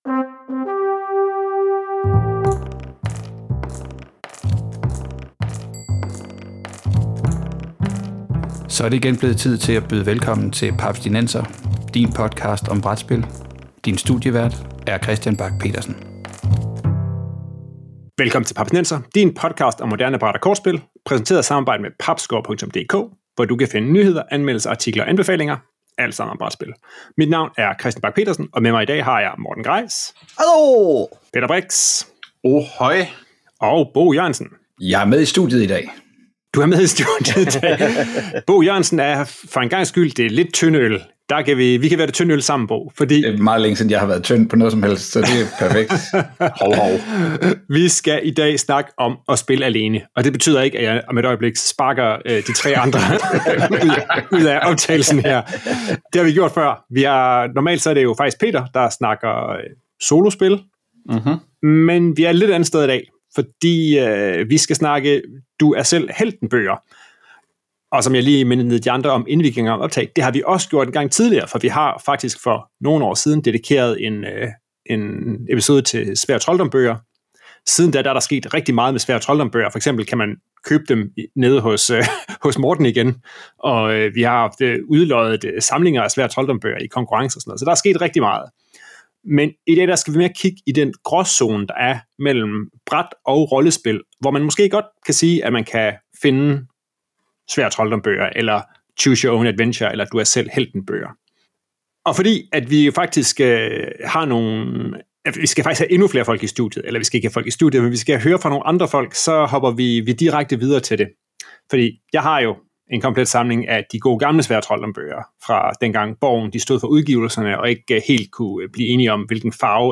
Solo, spillebøger og interviews fra UKGE 2024